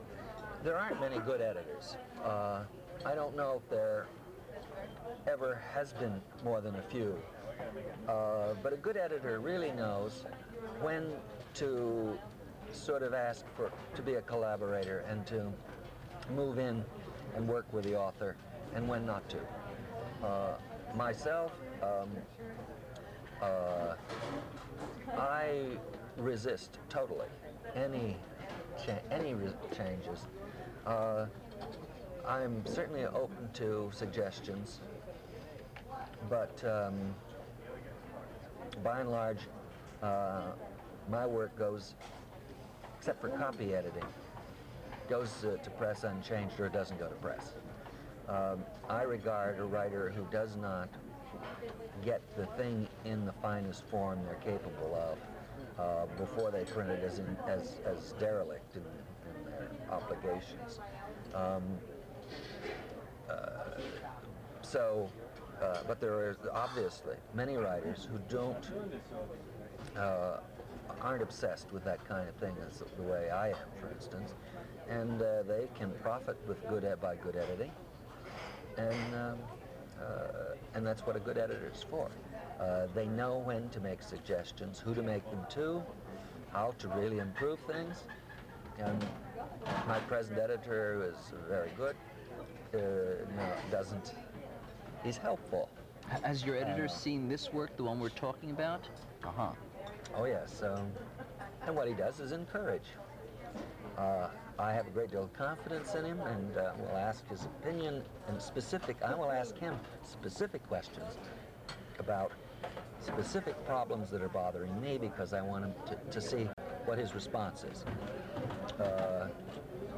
William Gass Interview at LAX Airport
Audio Cassette Tape